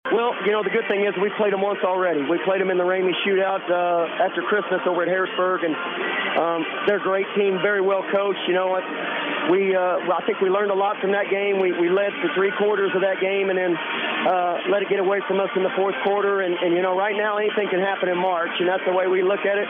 Head Coach